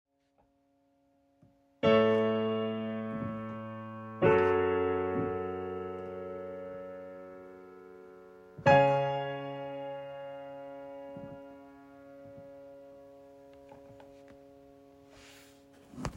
Wenn du die Phasenschwebung nach dem Anschlag meinst das ist eine normale Schwingung nach den Transienten.
Anbei mal ein Soundbeispiel von dem Galaxy Vintage D Softwarepiano dort geht die Schwingung durch weil die nicht geloopt ist wie bei dem Kurzweil Anhänge Vintage D.mp3 751,9 KB